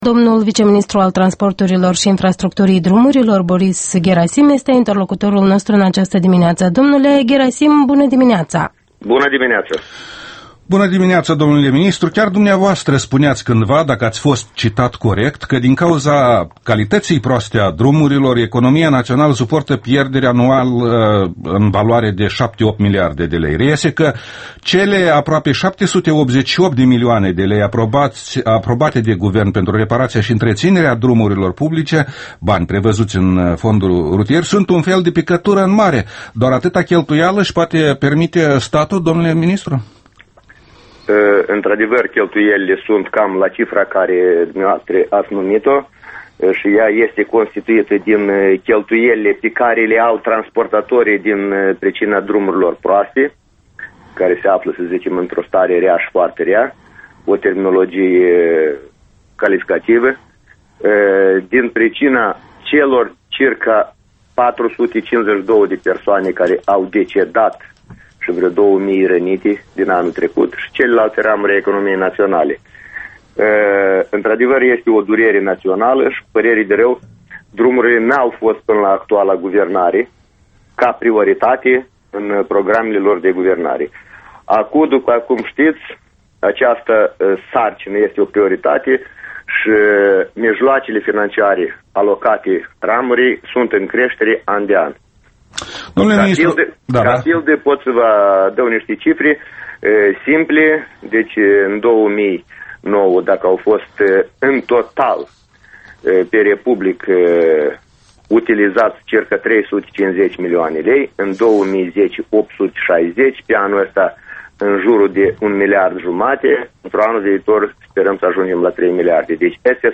Interviul matinal EL: cu viceministrul Boris Gherasim despre starea drumurilor